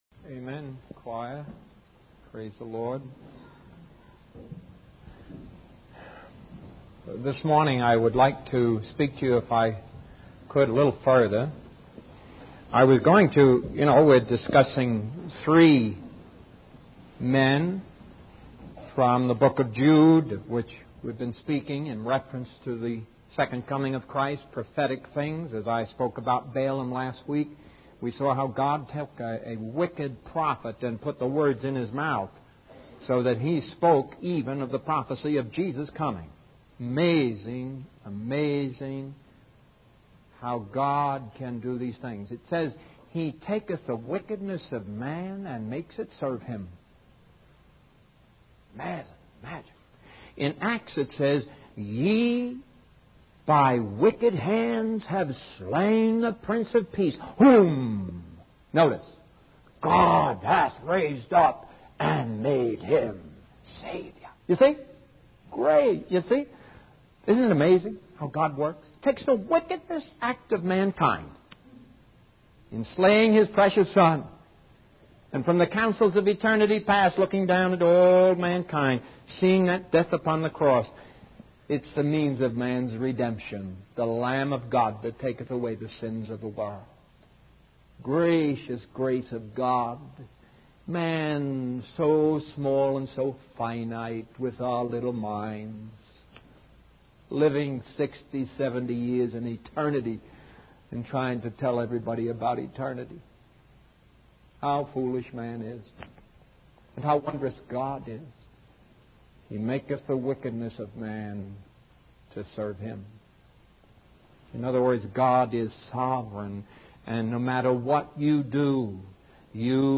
In this sermon, the pastor discusses the importance of love and compatibility in a marriage. He emphasizes the need for both partners to be born again and to deeply love each other.